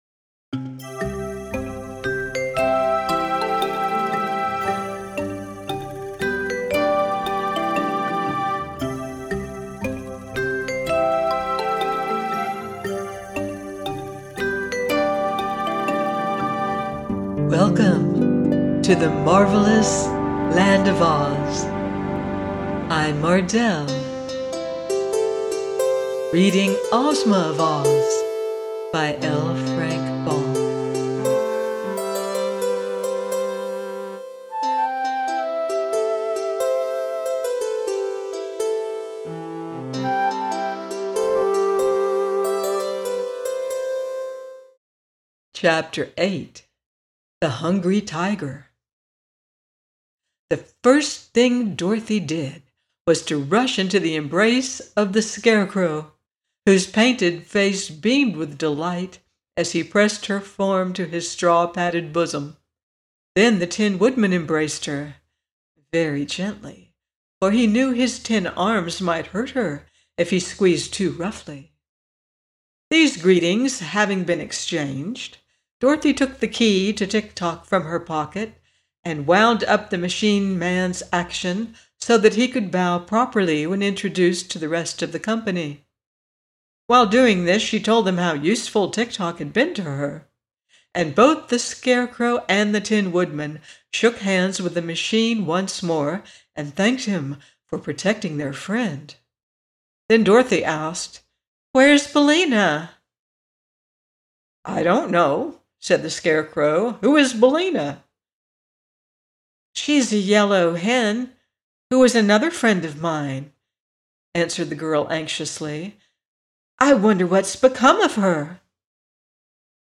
Ozma Of Oz – by L. Frank Baum - audiobook